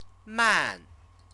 chinese_characters_man_man.mp3